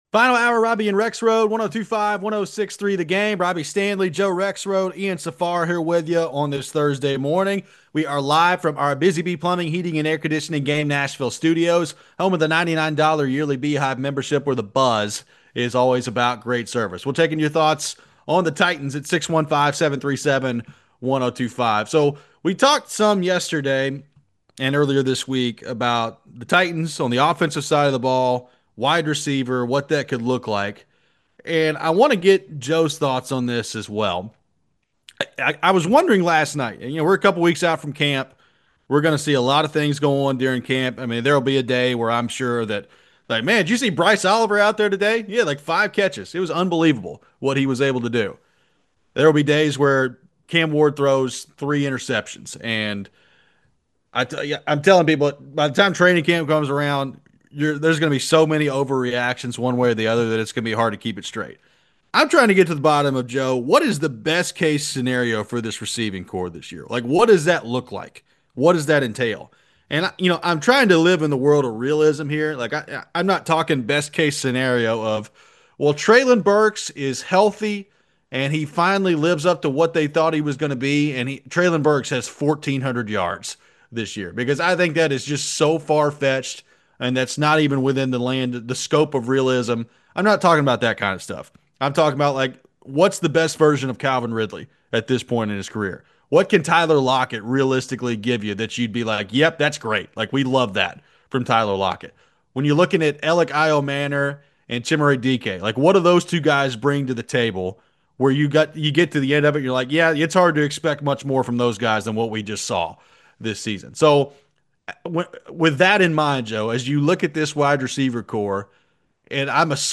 What are the best-case and worst-case scenarios for the Titans when it comes to the WRs this season? We take your phones on the Titans. What things are we going to pay attention to early on when it comes to Cam Ward at training camp?